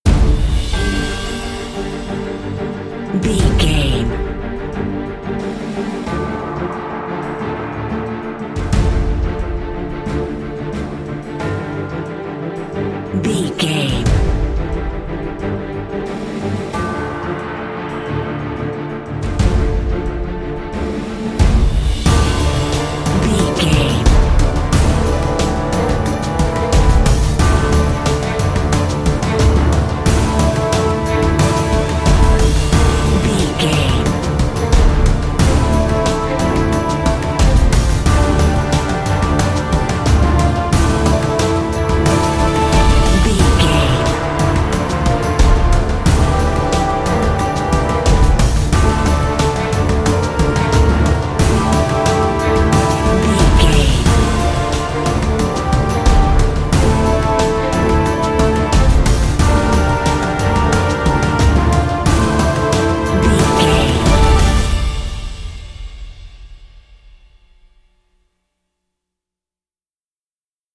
Epic / Action
Aeolian/Minor
D
strings
percussion
cello
violin
orchestra
synthesiser
orchestral hybrid
dubstep
aggressive
energetic
intense
synth effects
wobbles
driving drum beat